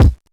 Medicated Kick 21.wav